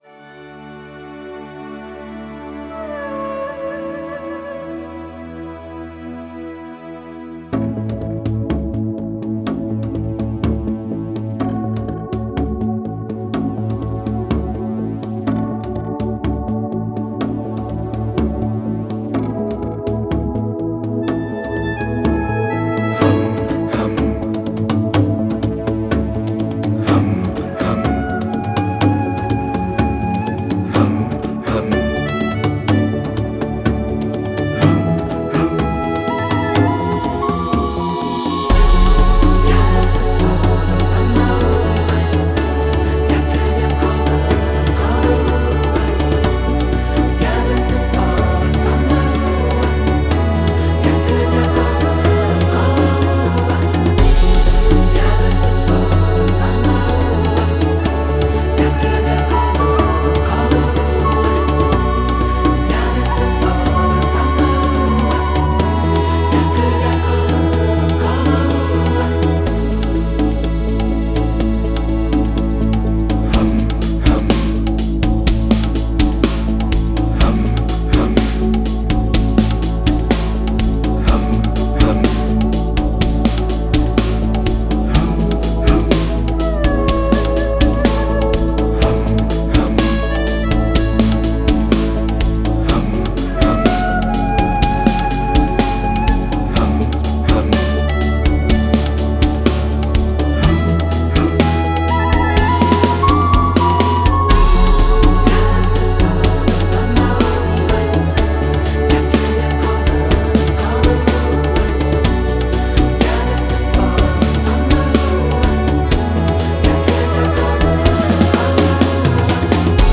La chanson proposée, simple et courte, permettait une reprise musicale.
Bande-son et partition pouvant être adaptées et interprétées selon les goûts actuels des élèves.